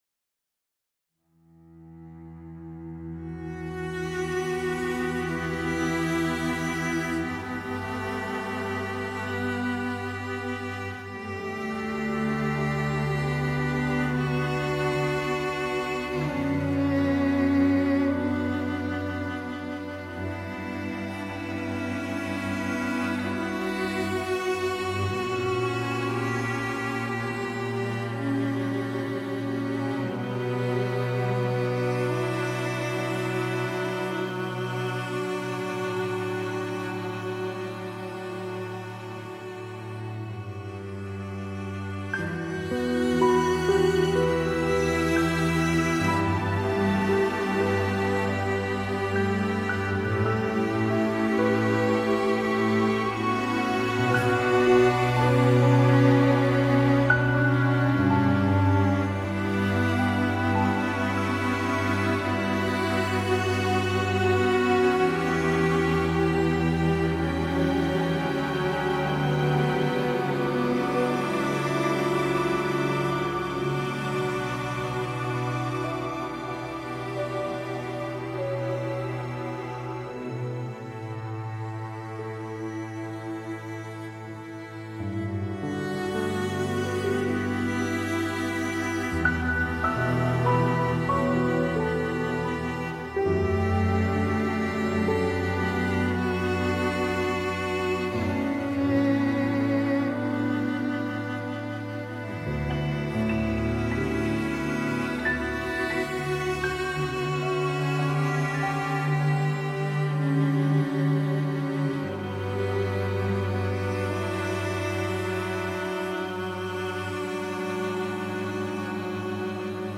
chamber strings and piano